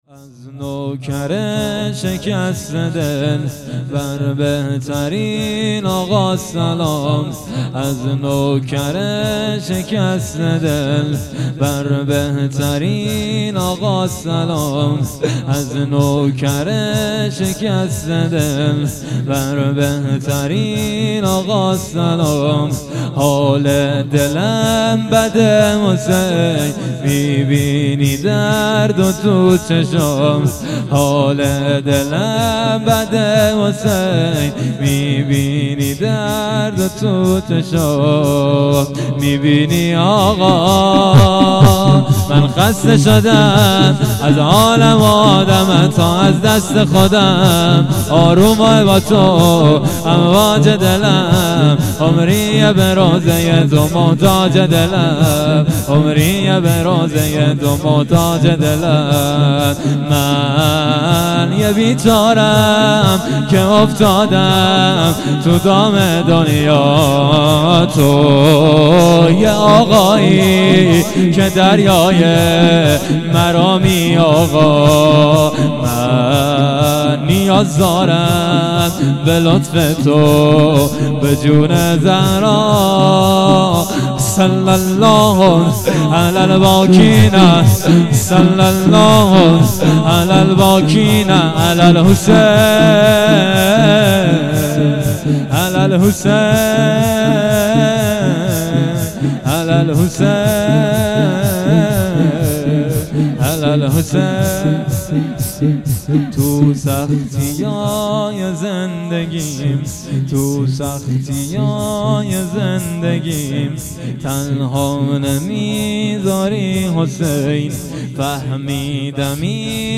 0 0 شور
شب سوم - دهه اول محرم 1400